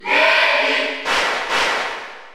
File:Lemmy Cheer Spanish PAL SSBU.ogg
Lemmy_Cheer_Spanish_PAL_SSBU.ogg.mp3